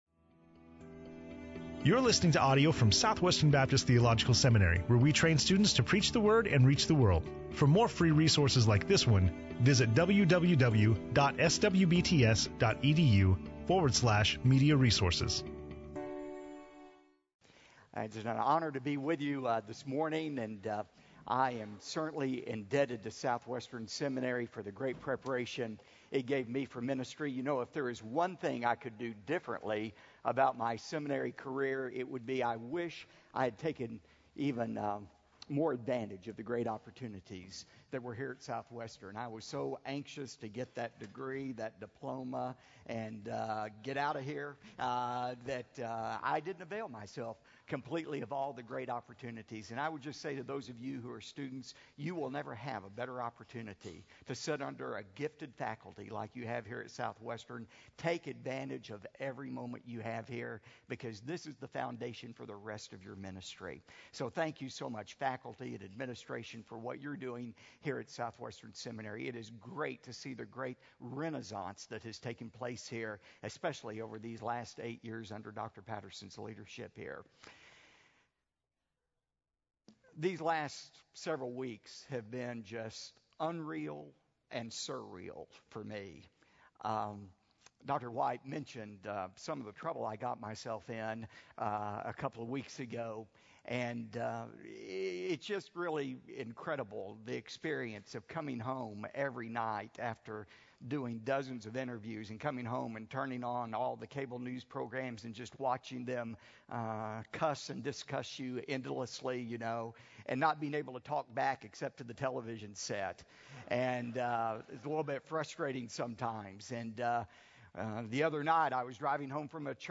Dr. Robert Jeffress speaking on Colossians 1:21-23 in SWBTS Chapel on Thursday October 27, 2011